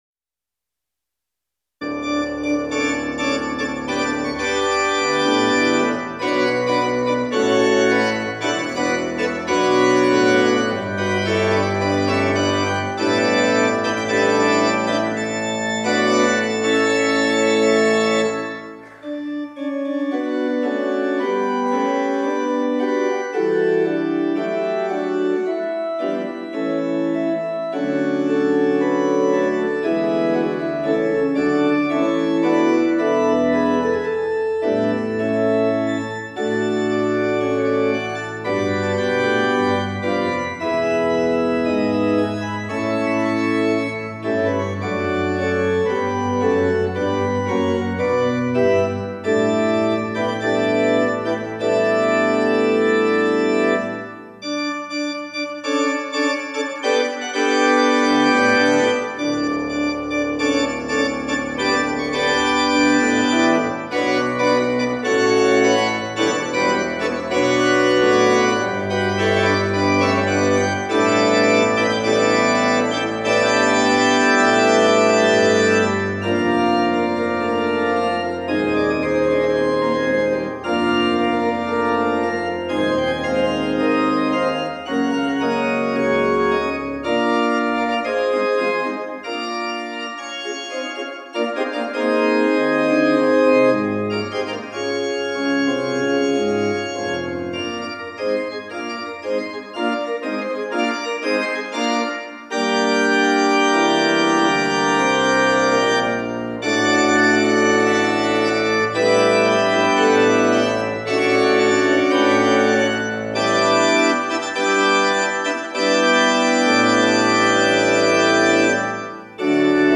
특송과 특주 - 면류관 벗어서